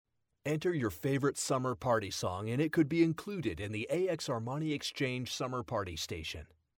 warm, friendly, guy next door, corporate
Sprechprobe: Werbung (Muttersprache):